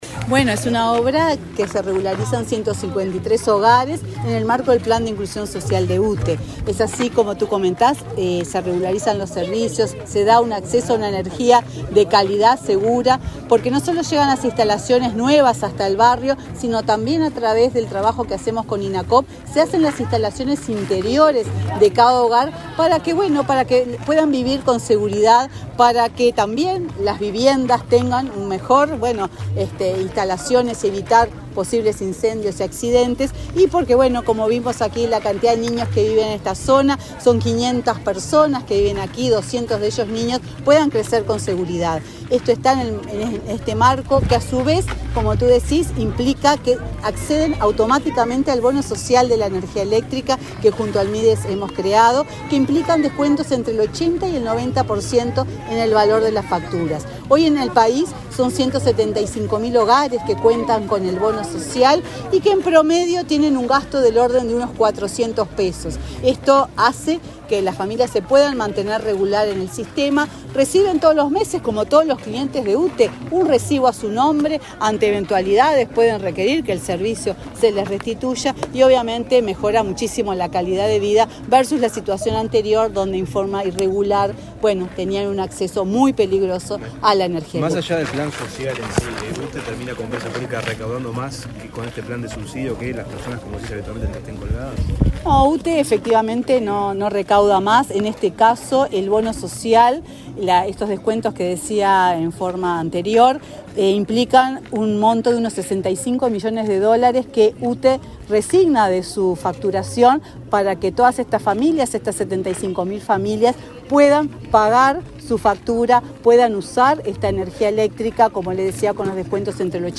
Declaraciones de la presidenta de UTE, Silvia Emaldi
Declaraciones de la presidenta de UTE, Silvia Emaldi 12/07/2024 Compartir Facebook X Copiar enlace WhatsApp LinkedIn La presidenta de UTE, participó, este viernes 12, en la inauguración de obras de electrificación realizadas por la empresa energética, en el barrio El Dorado de la ciudad 18 de Mayo, departamento de Canelones. Luego, dialogó con la prensa.